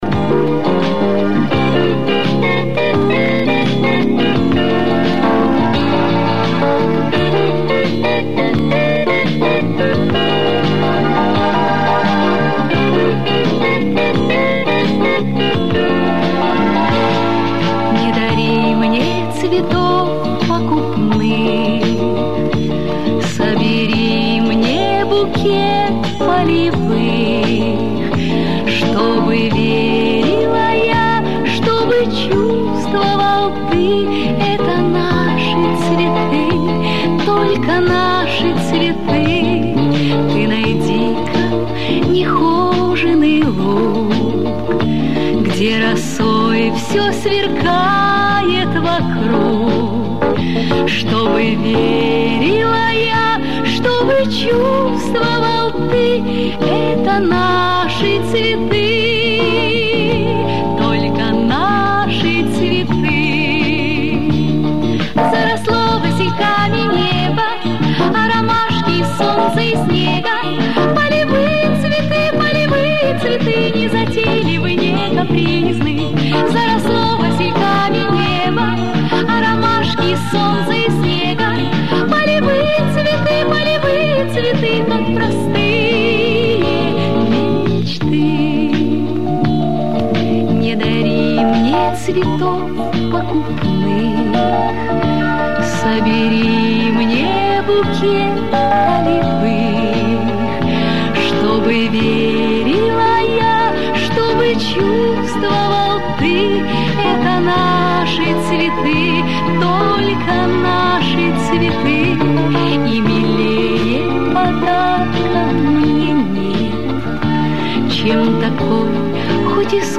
медленный вариант со стерео звуком